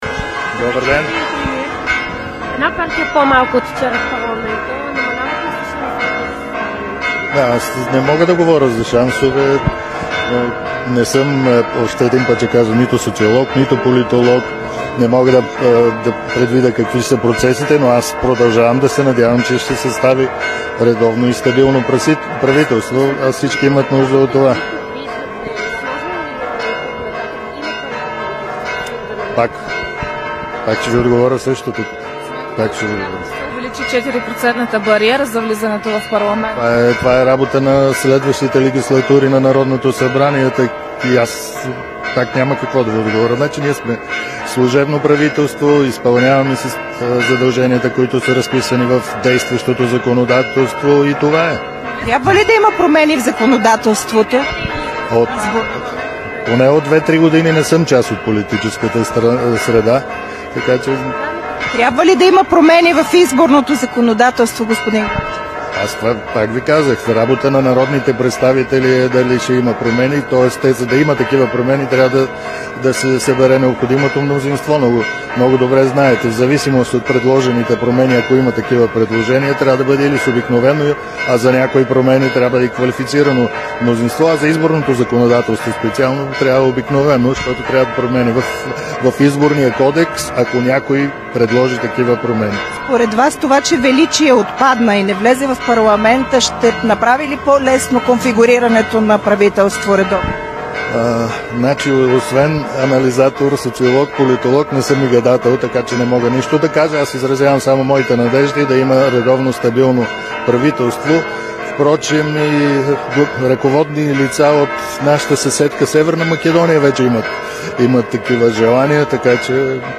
11.30 - Пресконференция на БСП „Обединена левица" за анализ на изборните резултати.
Директно от мястото на събитието